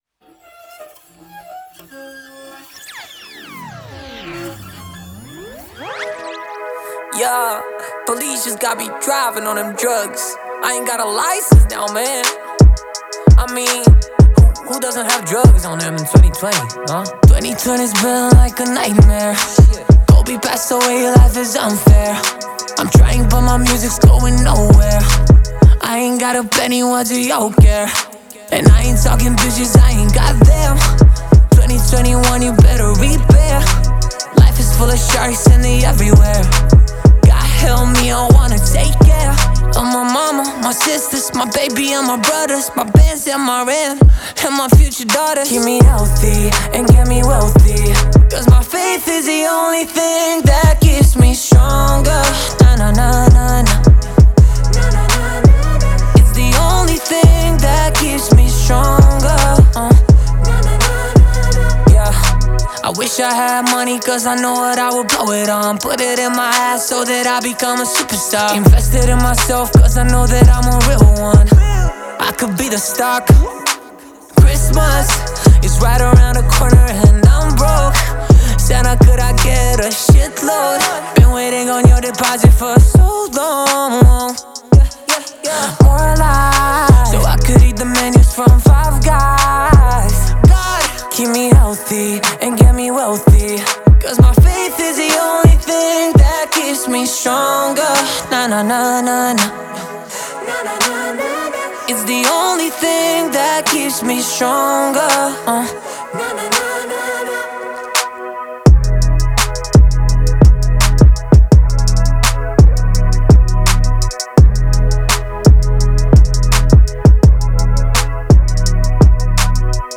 энергичная поп-песня
наполненная мощными ритмами и вдохновляющим настроением.